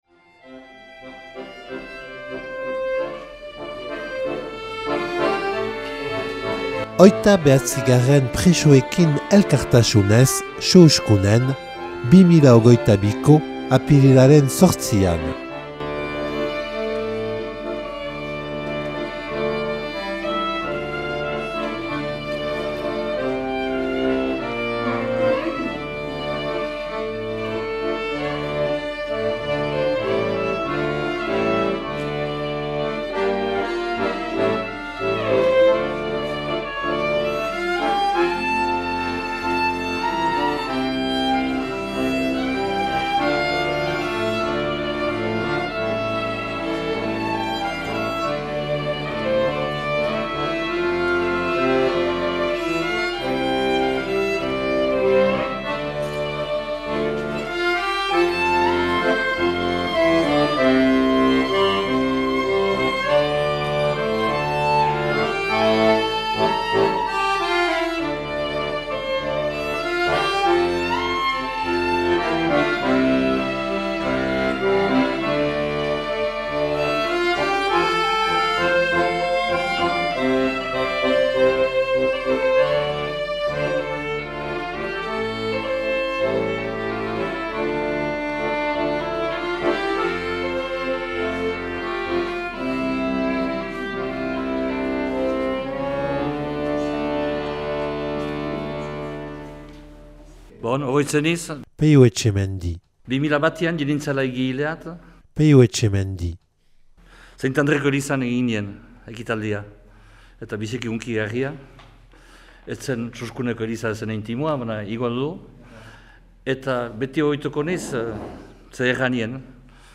Presoekin elkartasunez Suhuskunen 2022. apirilaren 8an